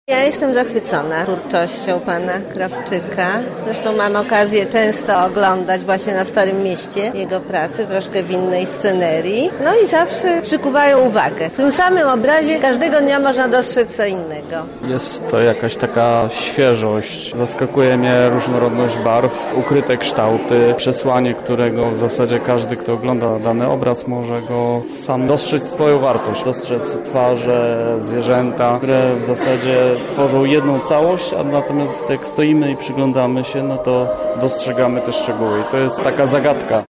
Nasz reporter zapytał uczestników o wrażenia.